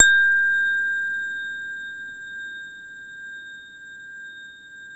WHINE  A#4-L.wav